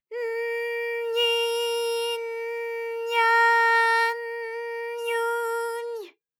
ALYS-DB-001-JPN - First Japanese UTAU vocal library of ALYS.
ny_n_nyi_n_nya_n_nyu_ny.wav